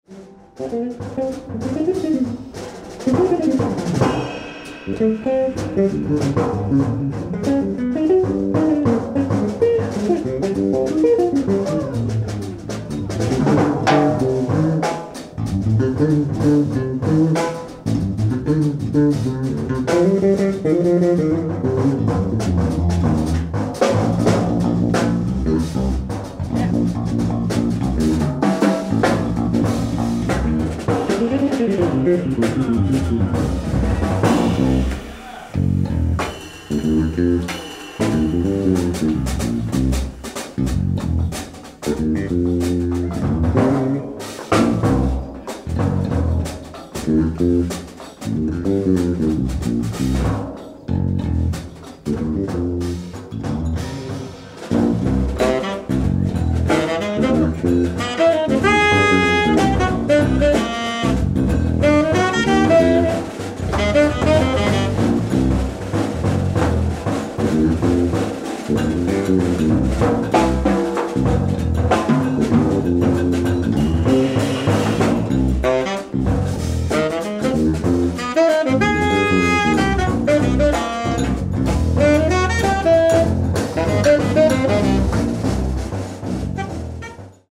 ライブ・アット・バードランド、ニューヨーク 05/24/2019
※試聴用に実際より音質を落としています。